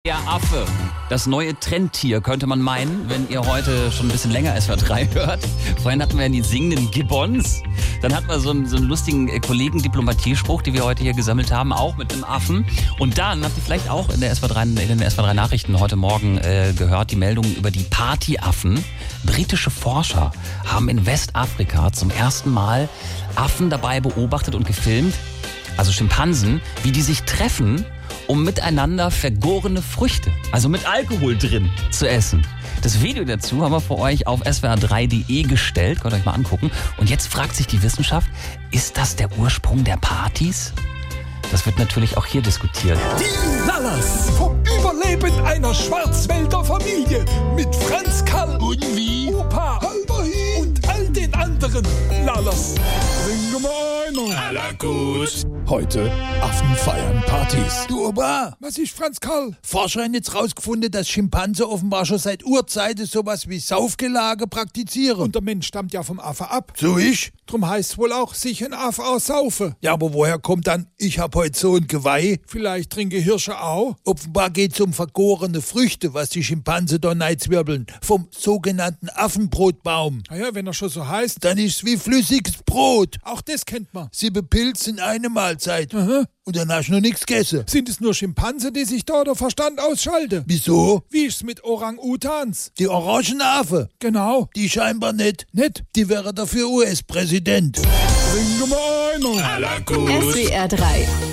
Übrigens: Auch die Lallers aus der SWR3 Comedy haben mittlerweile von der Sache mit den Affen Wind bekommen!